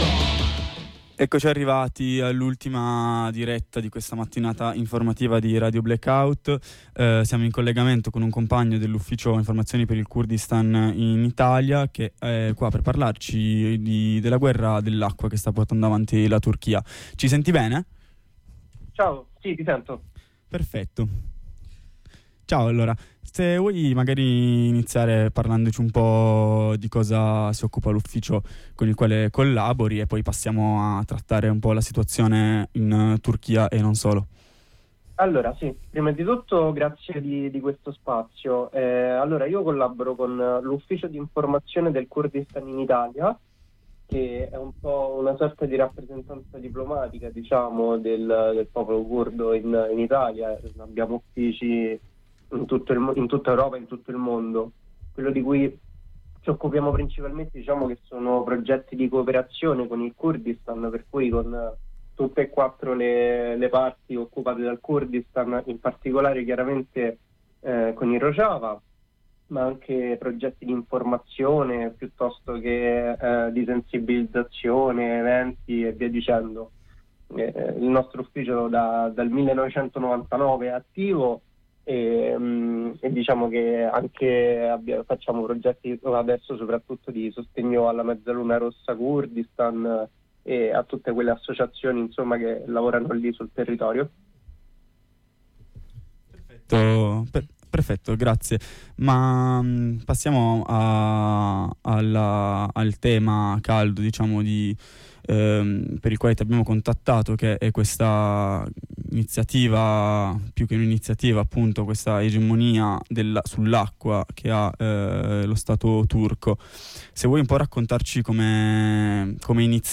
Ai microfoni di Radio Blackout ne ha parlato un compagno di UIKI, l’ufficio informazione per il Kurdistan in Italia.